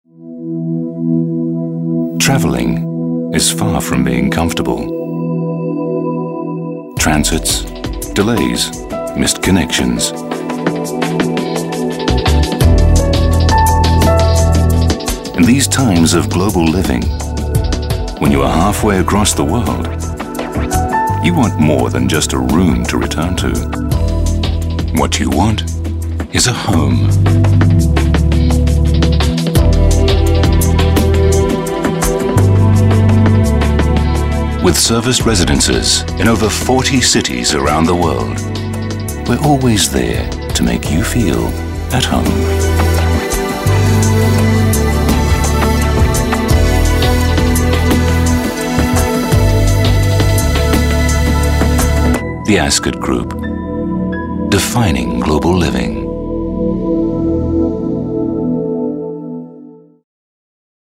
Not American but not quite British either, I have a neutral accent that has been well received in continental Europe precisely because it is neither.
English - Transatlantic
Middle Aged